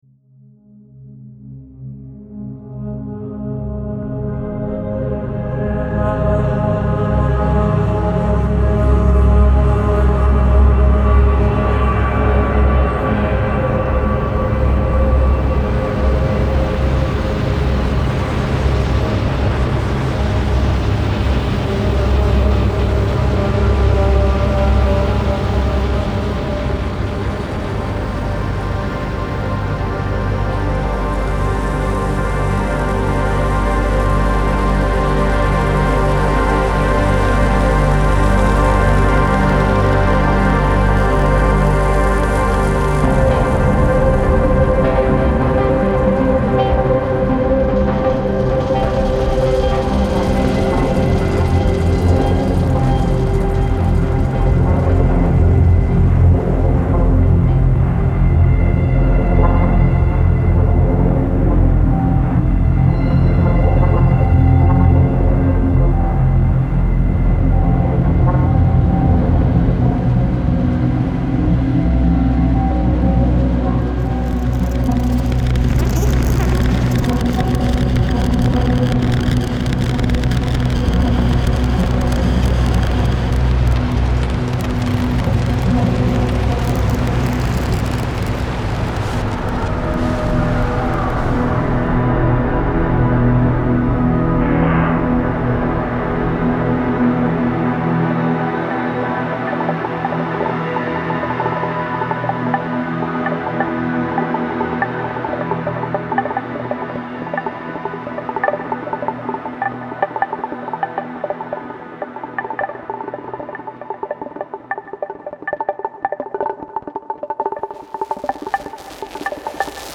Huge, crashing drones fusing synths with heavily processed acoustic instrumentation; thick textures whirring with noise and swerving between your speakers; waves of splintering, granular sound, pouring forth and engulfing all in their path; this is what you can expect from the pack’s 110 infinitesimally detailed audio files.